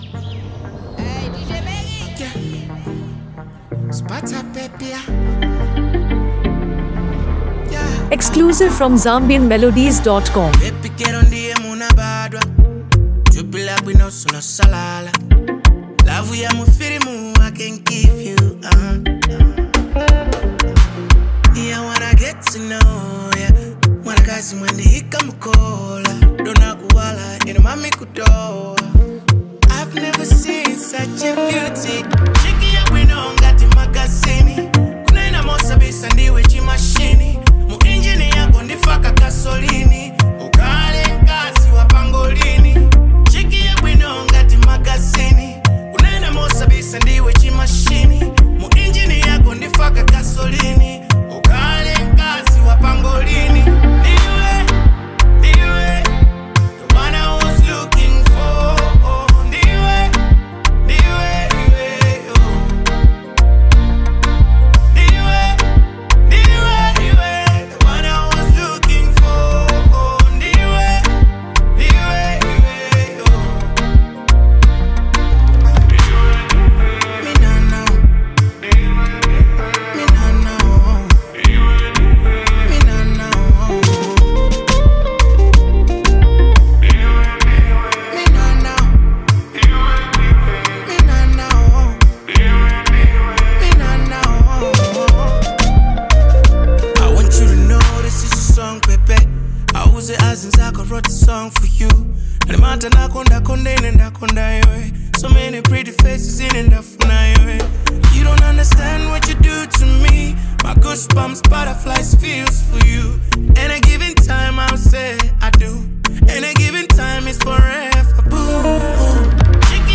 Genre: Afro-Fusion / RnB